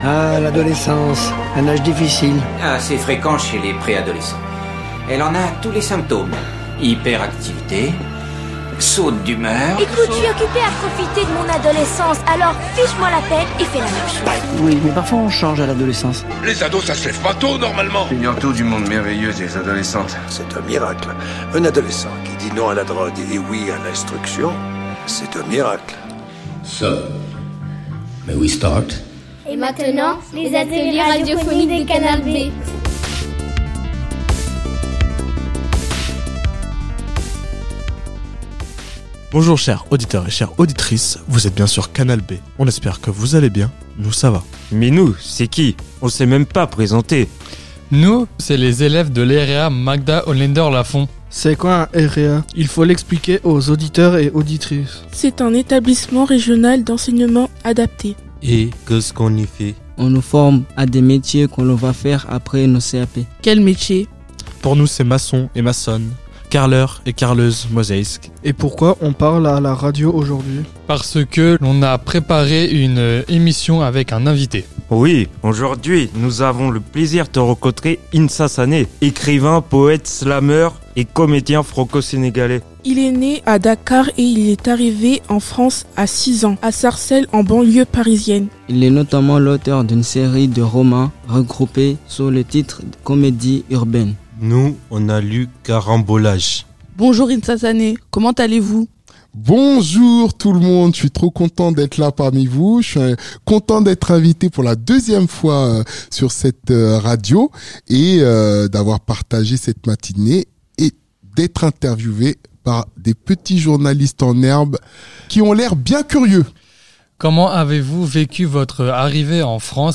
Il n'y en a pas toutes les semaines, mais c'est sur ce créneau que nous vous proposons d'écouter les productions réalisées durant les ateliers d'éducation aux média radio mis en place par Canal B.
Depuis plus de quatre ans, Canal B travaille très régulièrement avec l’association Langue et Communication et vous avez pu entendre ici des émissions réalisées avec des personnes migrantes venues d’Afghanistan, de Syrie, d’Iran, d’Irak, de Georgie, d’Ukraine, du Mexique, d’Argentine, du Soudan, d’Erythrée, du Maroc, de Mongolie, de Thaïlande et d’autres pays encore